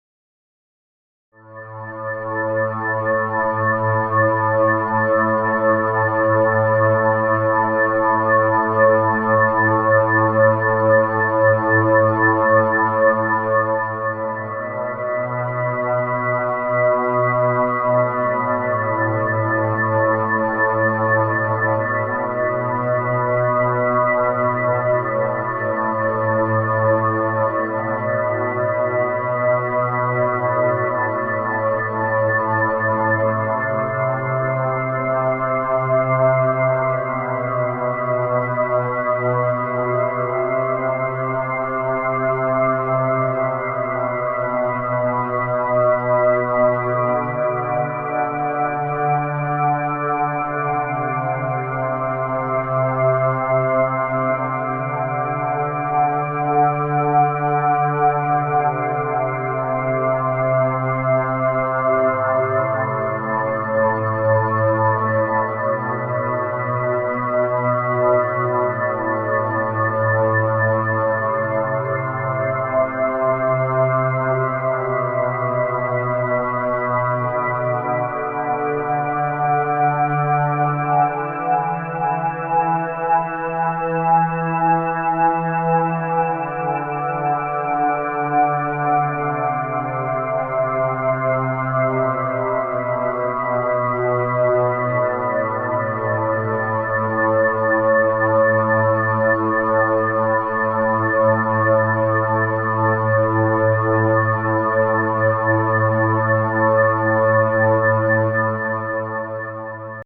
Music / Trance
synth orchestra ambient new age orchestral peyote meditation peaceful synthesizer electric wave